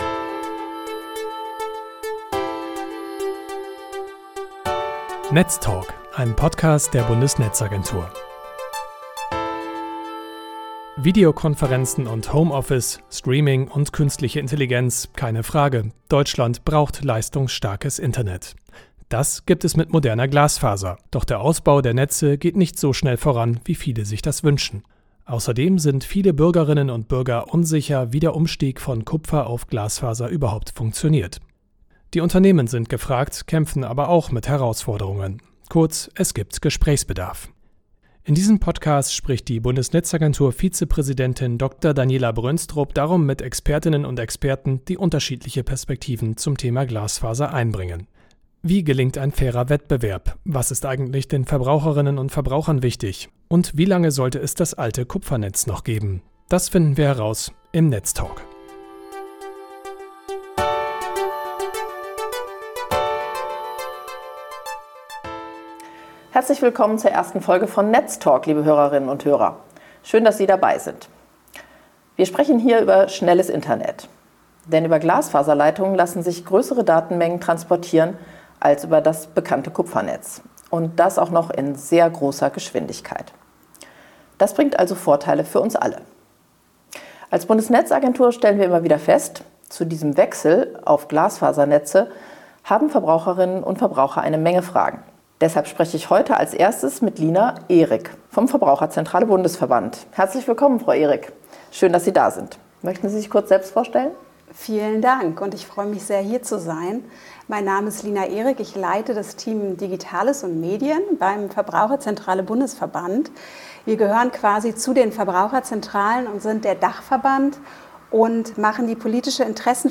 In der ersten Folge von NETZtalk spricht Dr. Daniela Brönstrup, die Vizepräsidentin der Bundesnetzagentur